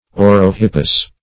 Search Result for " orohippus" : The Collaborative International Dictionary of English v.0.48: Orohippus \Or`o*hip"pus\, n. [NL., fr. Gr.